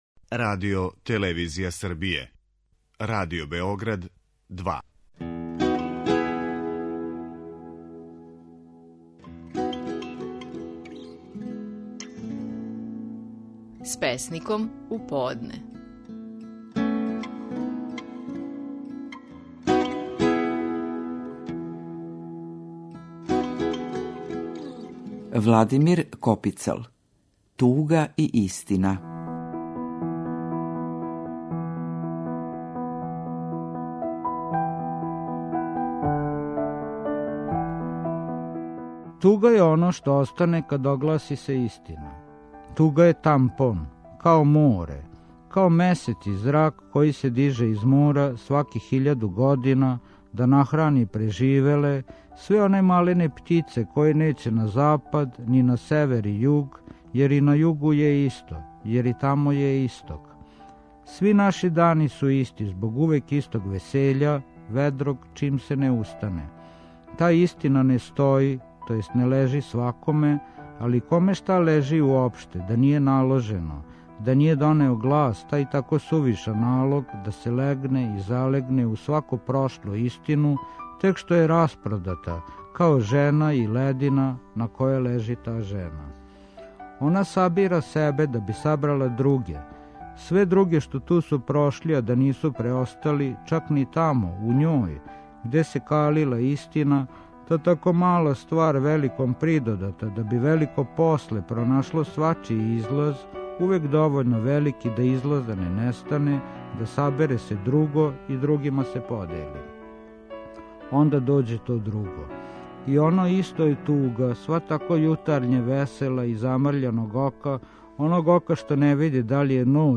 Наши најпознатији песници говоре своје стихове
Владимир Копицл говори своју песму „Туга и истина".